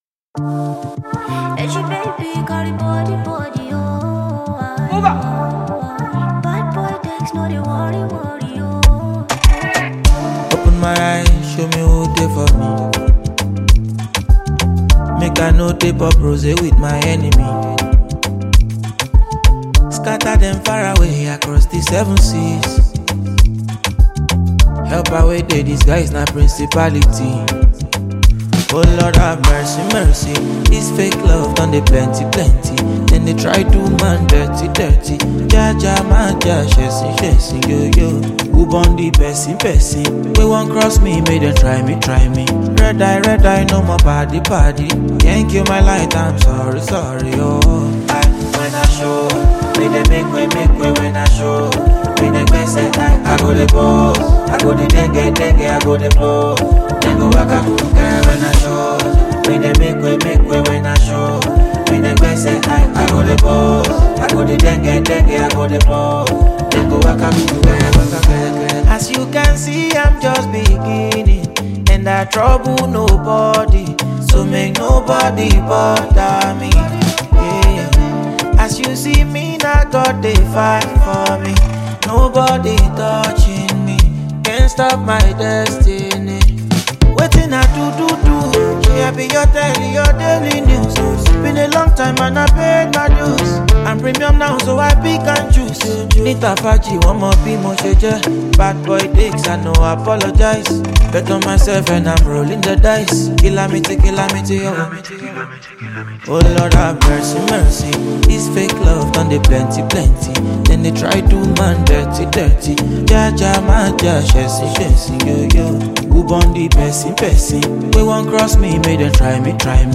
Afro classic